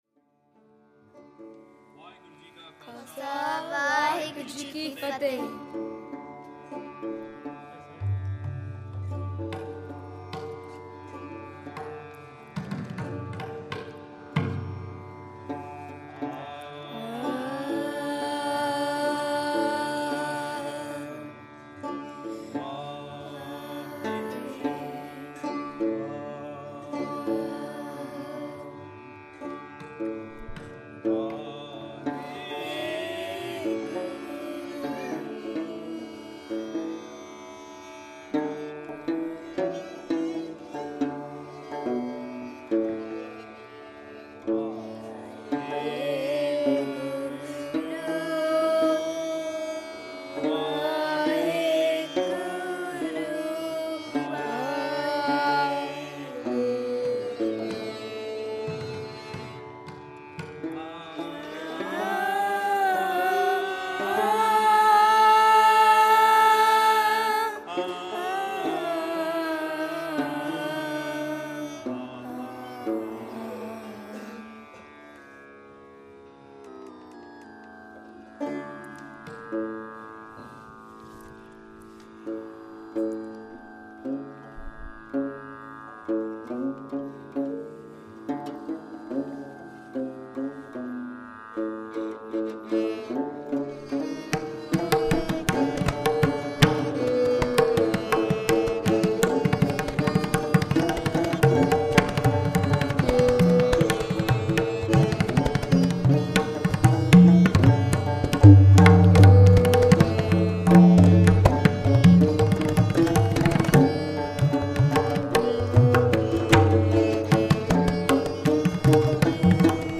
8.2min/7.4MB Kedara / Kedara